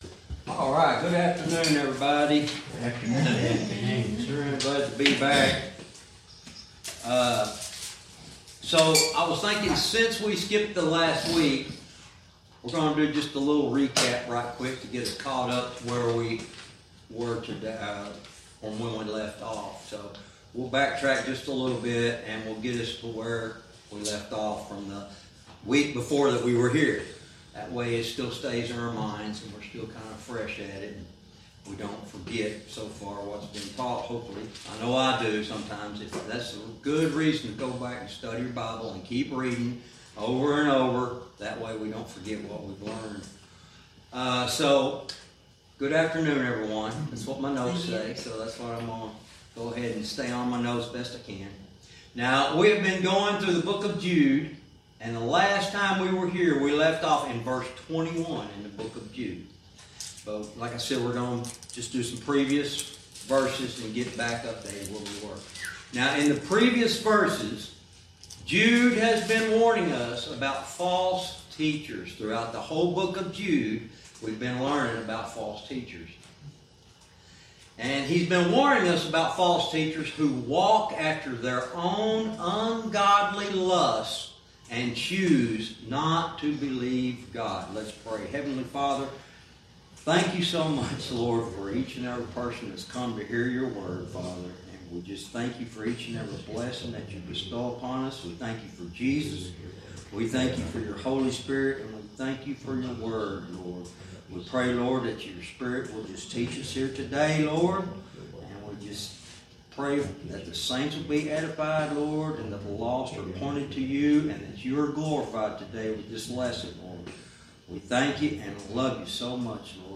Verse by verse teaching - Jude lesson 97 verse 21